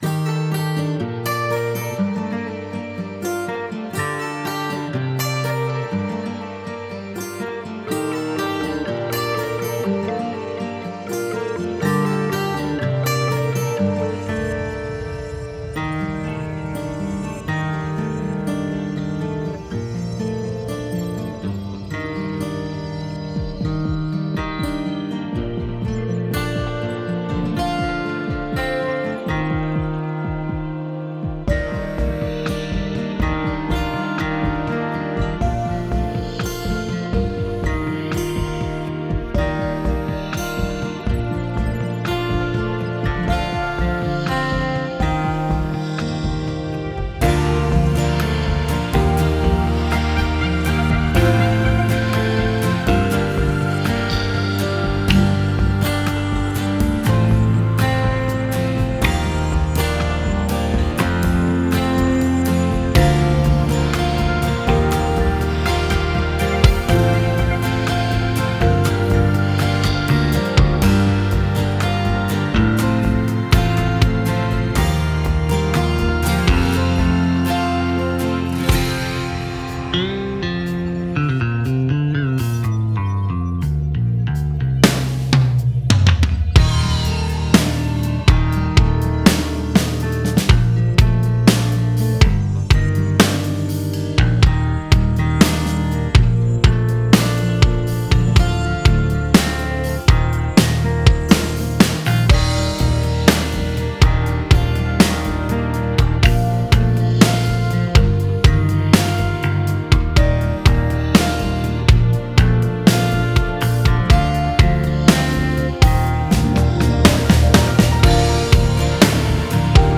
на пение не обращайте внимание , парень совсем петь не умеет и слава богу это понимает ) но хочется)